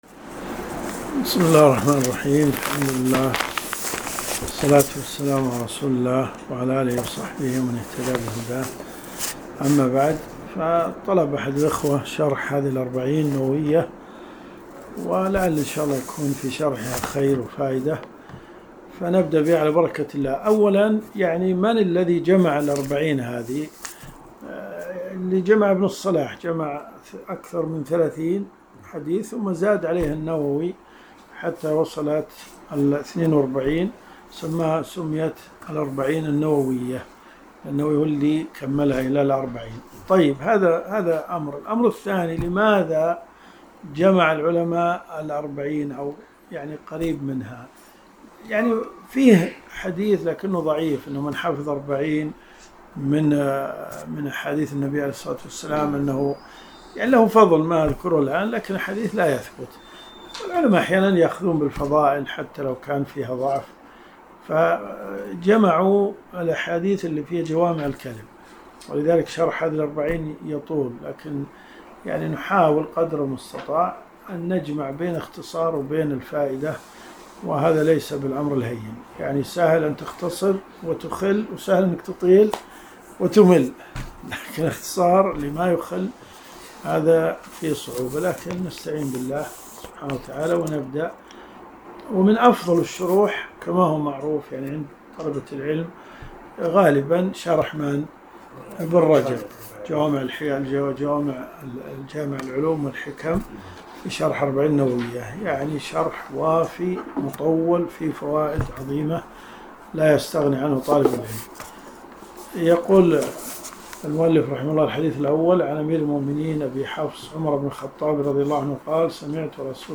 الرئيسية الدورات الشرعية [ قسم الحديث ] > الأربعون النووية . 1444 .